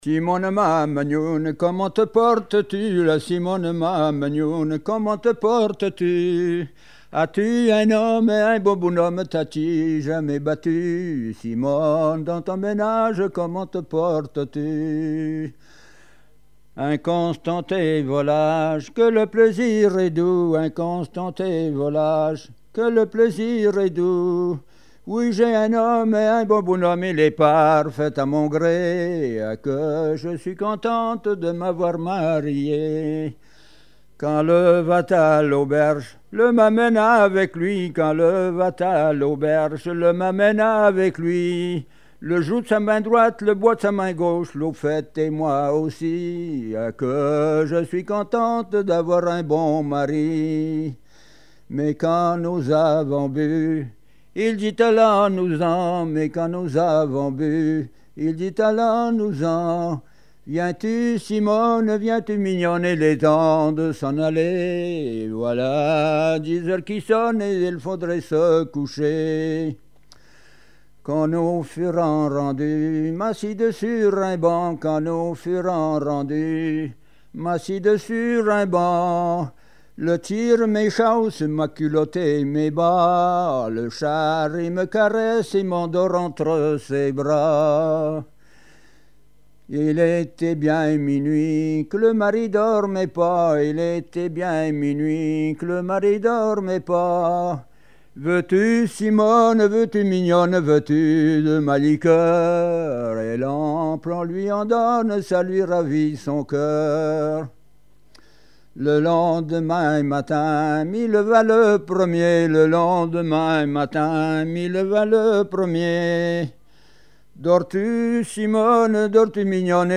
Mémoires et Patrimoines vivants - RaddO est une base de données d'archives iconographiques et sonores.
Genre dialogue
Répertoire de chansons traditionnelles et populaires